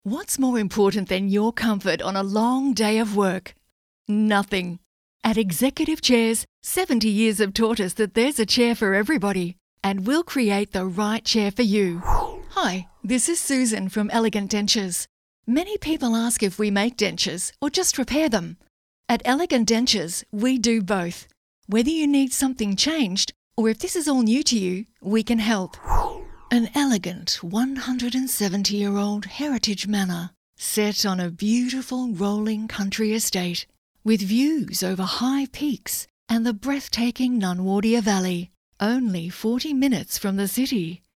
• Rode Procaster mic